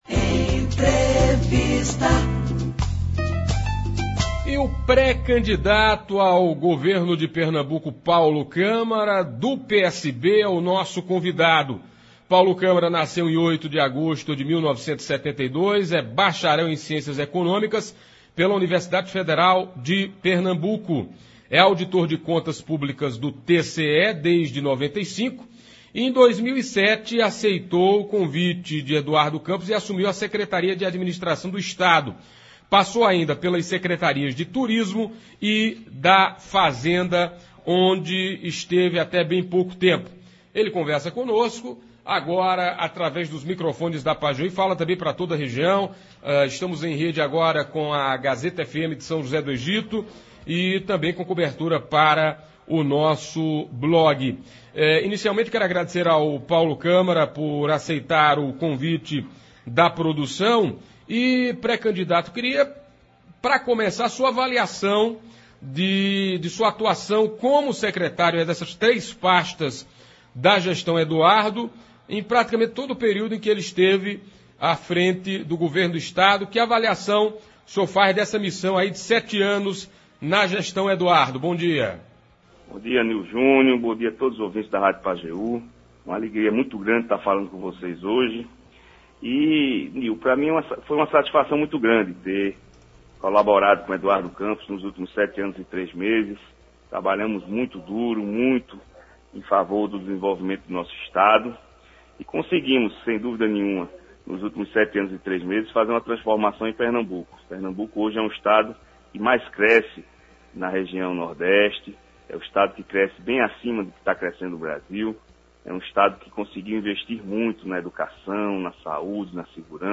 Em primeira entrevista cedida a uma rádio da região do Pajeú, o pré-candidato ao governo de Pernambuco Paulo Câmara (PSB), falou agora a pouco ao programa Manhã Total.